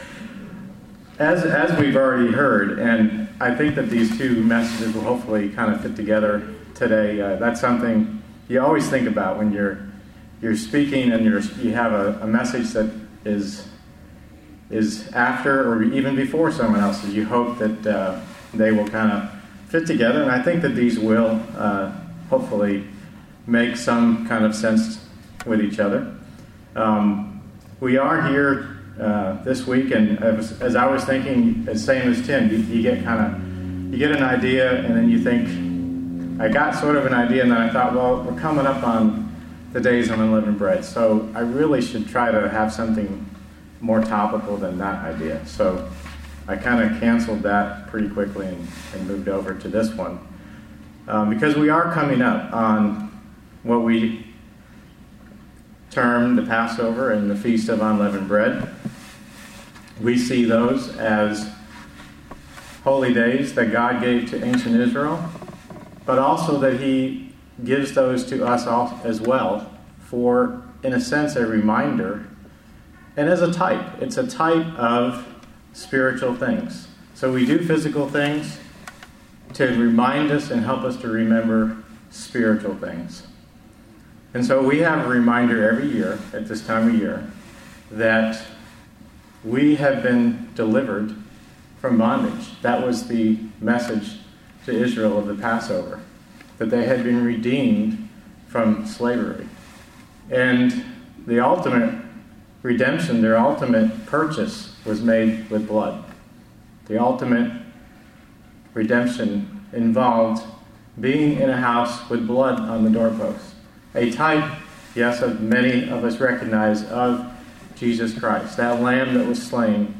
Sermons
Given in Murfreesboro, TN